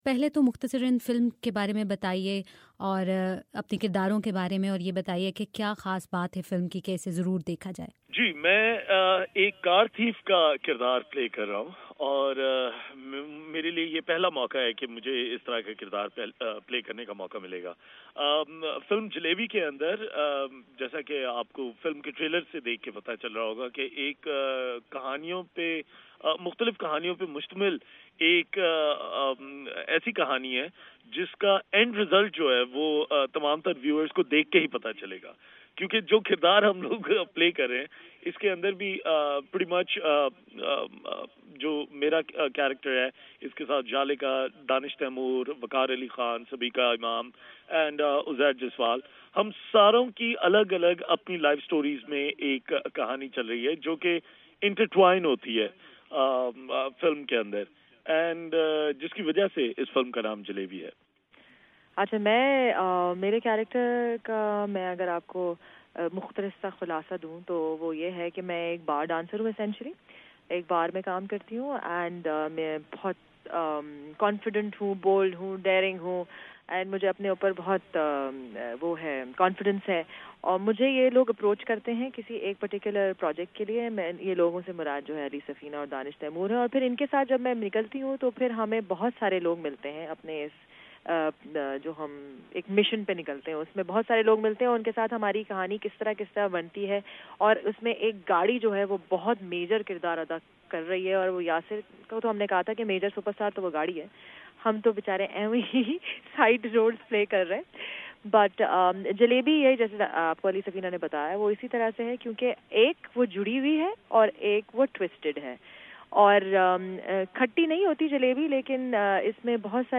خصوصی گفتگو